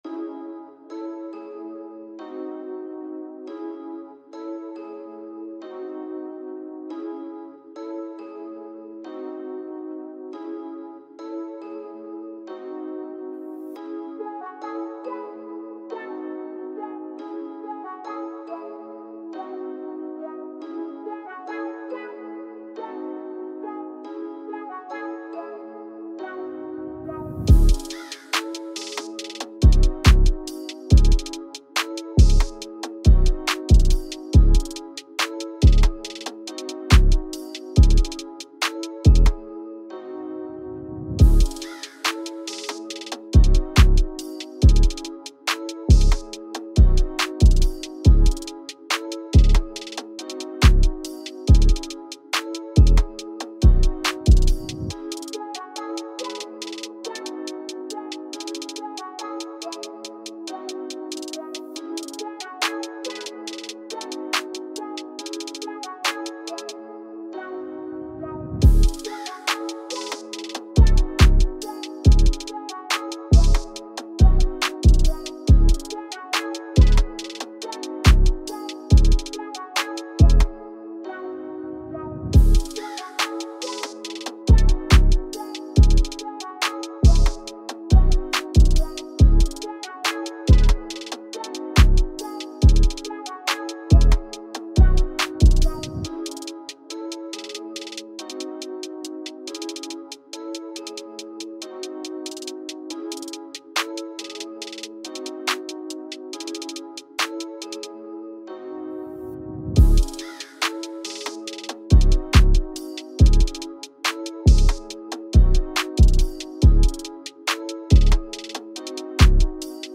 Free Beats instrumental
in C# minor